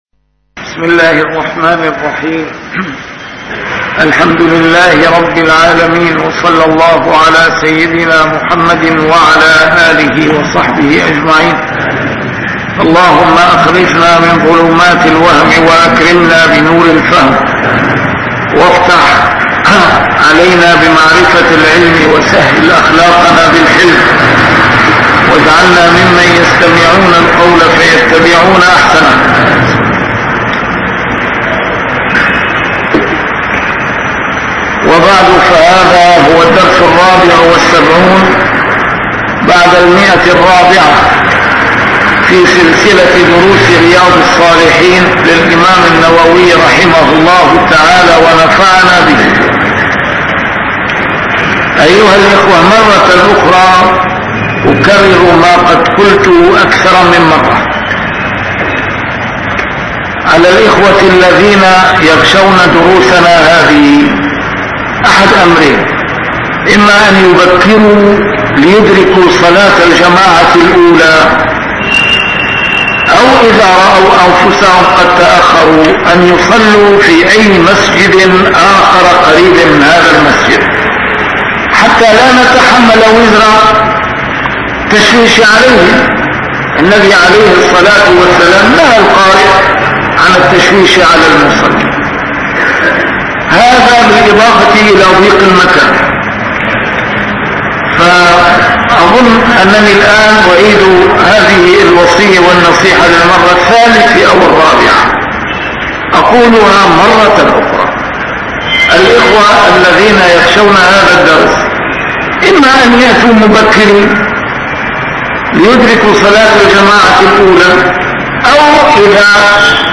A MARTYR SCHOLAR: IMAM MUHAMMAD SAEED RAMADAN AL-BOUTI - الدروس العلمية - شرح كتاب رياض الصالحين - 474- شرح رياض الصالحين: الرجاء